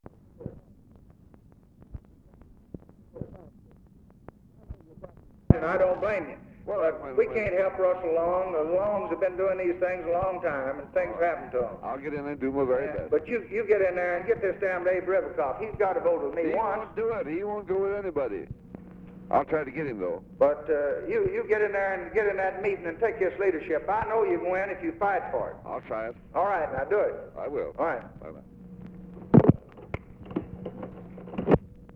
Conversation with CLINTON ANDERSON, January 23, 1964
Secret White House Tapes